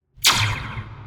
Revolver.wav